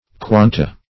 Quanta - definition of Quanta - synonyms, pronunciation, spelling from Free Dictionary